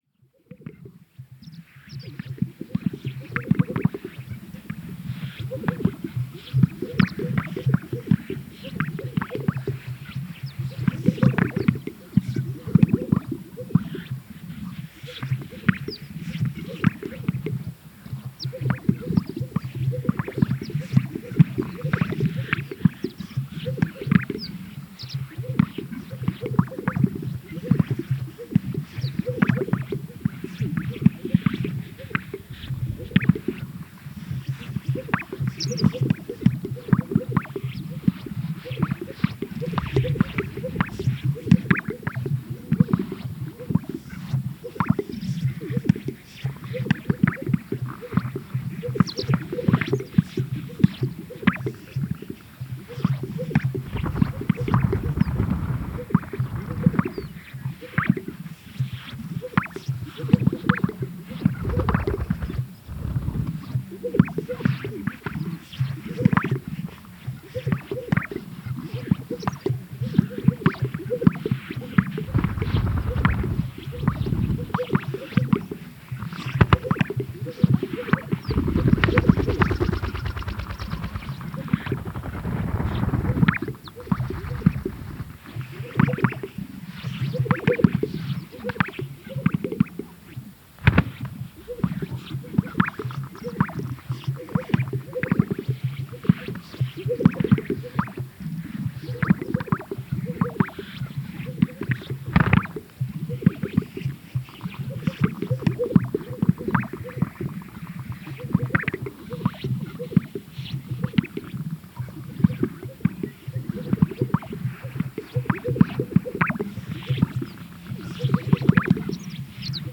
BIRDMisc_greater sage grouse lek 2_TK_SASSMKH8020 Sound Effect — Free Download | Funny Sound Effects
Lots and lots of male Greater Sage Grouse performing for a few